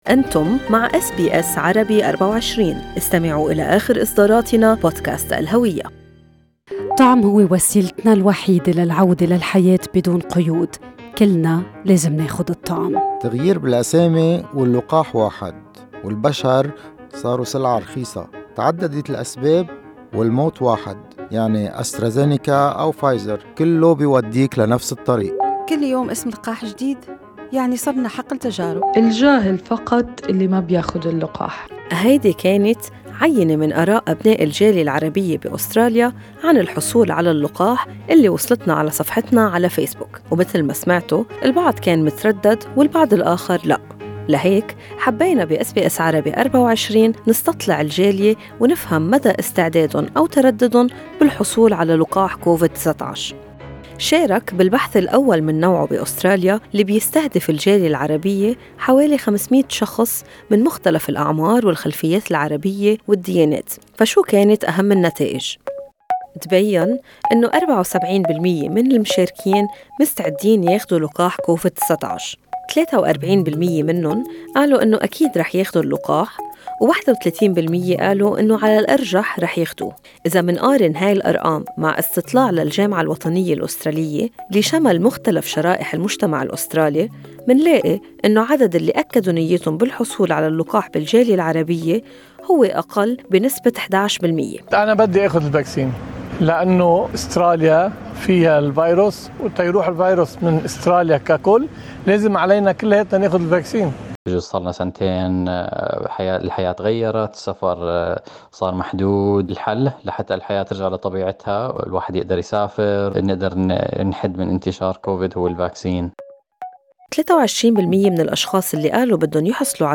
استمعوا إلى التقرير الصوتي في أعلى الصفحة أو توجهوا إلى هذا الموقع لقراءة كافة نتائج الاستطلاع.